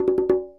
44 Bongo 17.wav